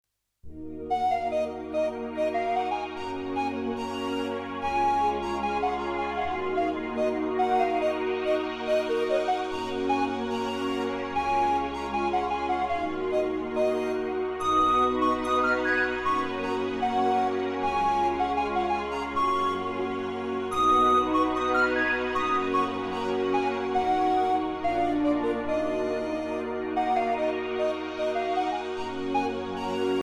Musique Celtique: